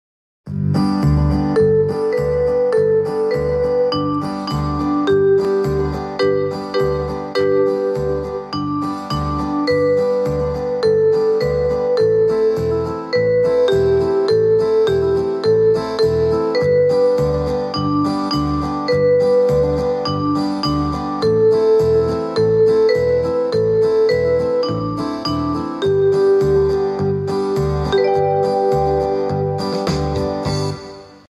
Nhạc beat bài hát: Mùa hè đến.